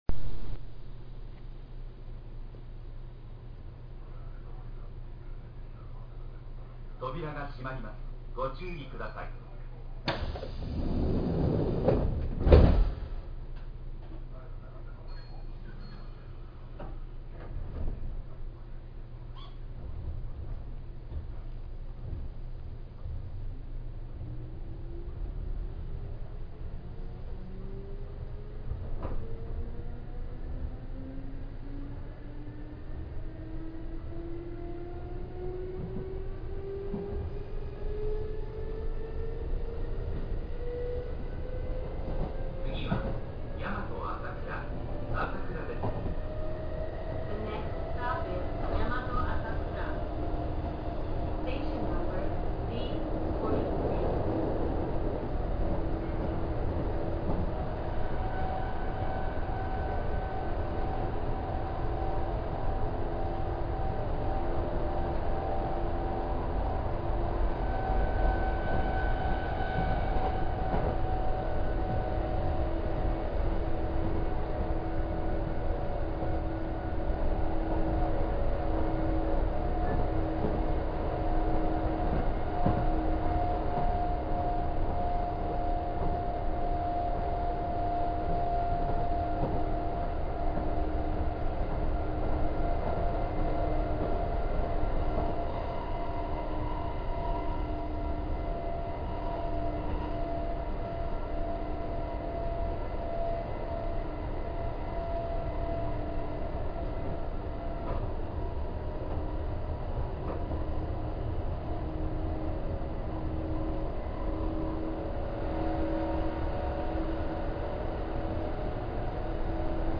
・2610系走行音
【大阪線】長谷寺→大和朝倉（3分48秒：1.74MB）
急行運用などに入ればかなり速度も出すので、派手な音が収録できます。